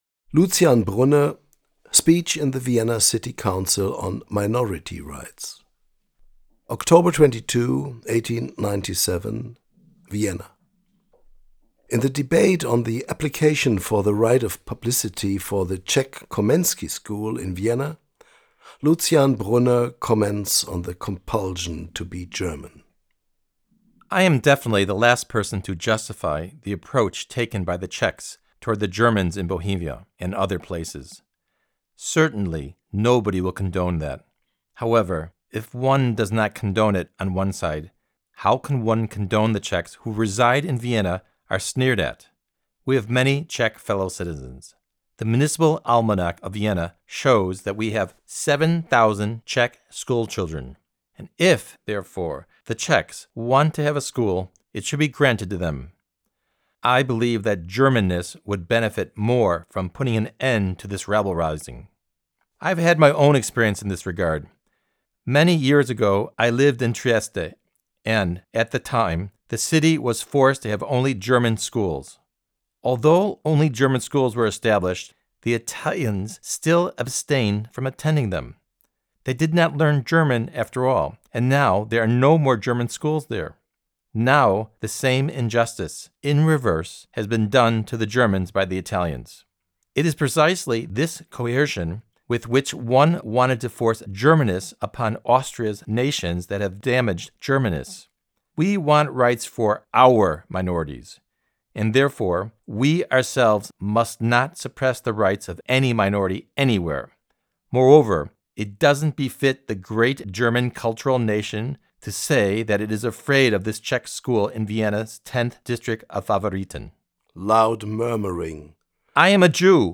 Lucian Brunner, speech in the Vienna City Council about minority rights in Vienna and Trieste – on the occasion of the planned extension of the Czech Komensky-School in Vienna-Favoriten. Vienna, October 22, 1897.
4_Lucian_Brunner_Speech_about_Minority_Rights_E.mp3